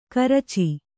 ♪ karaci